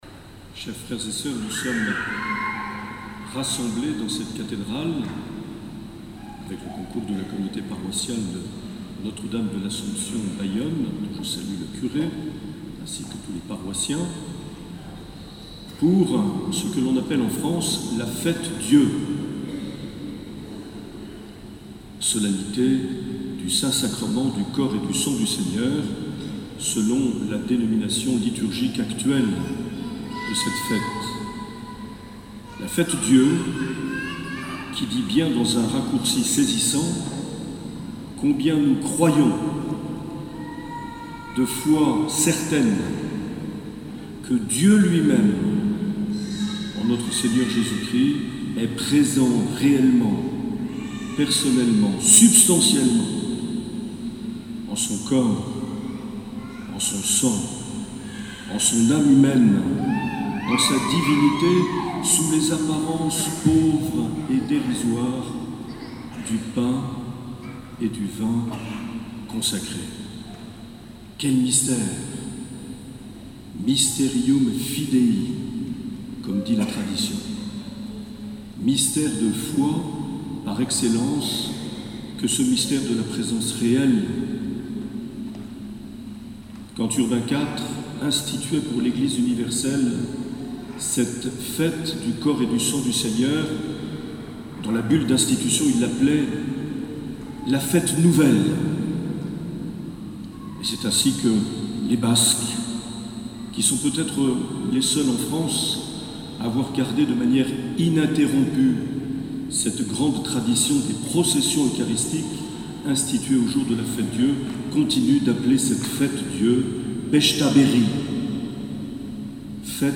23 juin 2019 - Cathédrale de Bayonne - Prêtres jubilaire - Admission au sacerdoce.
Accueil \ Emissions \ Vie de l’Eglise \ Evêque \ Les Homélies \ 23 juin 2019 - Cathédrale de Bayonne - Prêtres jubilaire - Admission au (...)
Une émission présentée par Monseigneur Marc Aillet